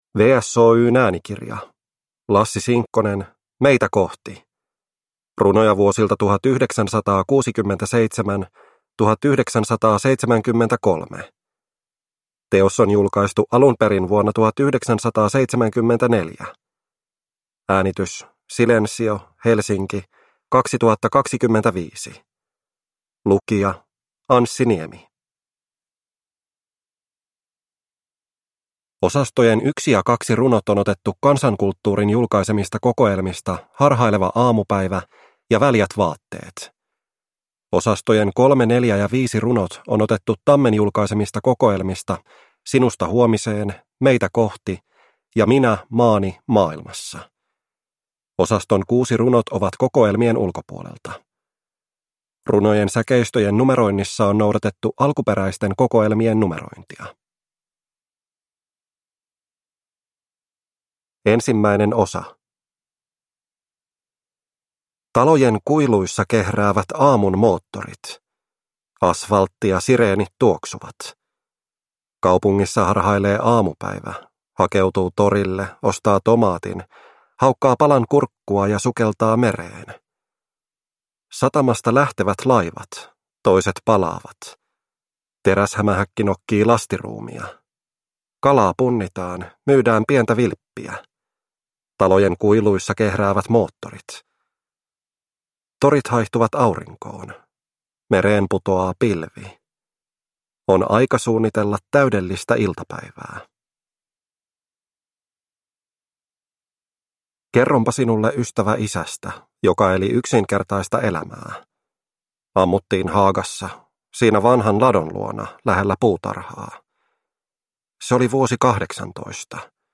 Meitä kohti – Ljudbok
Lyrik Njut av en bra bok